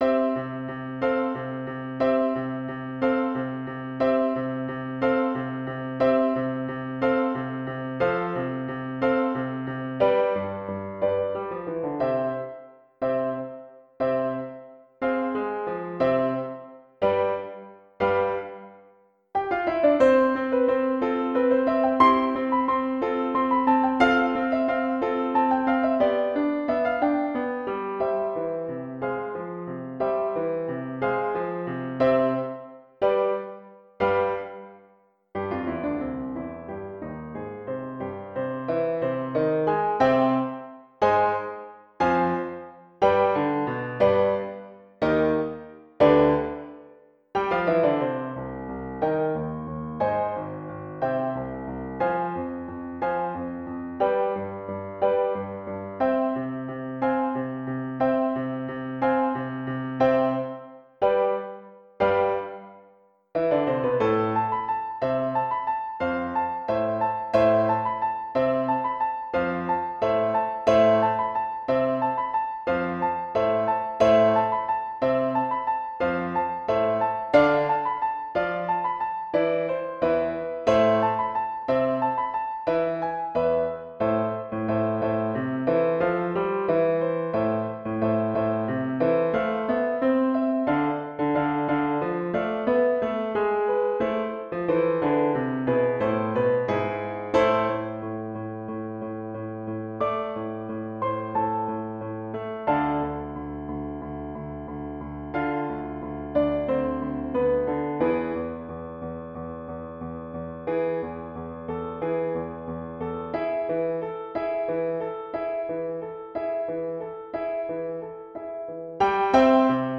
Audio: Piano part alone (slower)